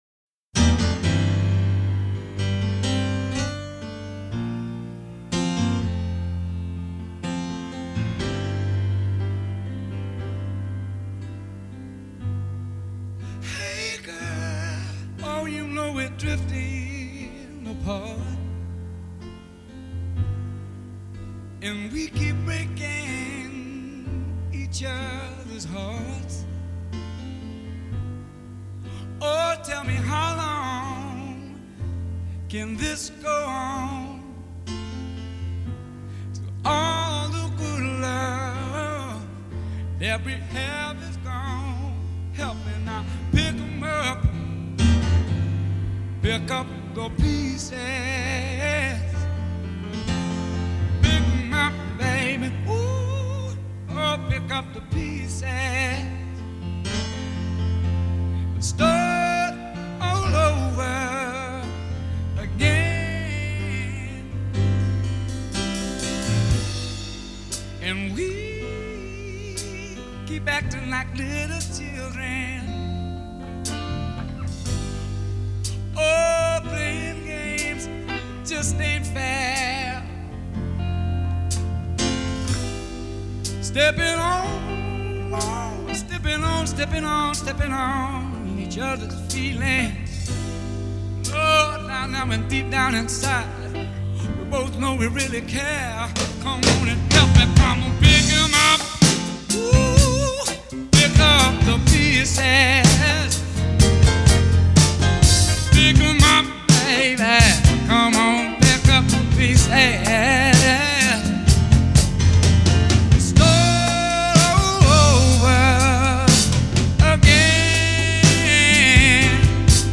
starts off slowly
funky, powerful, and absolutely golden.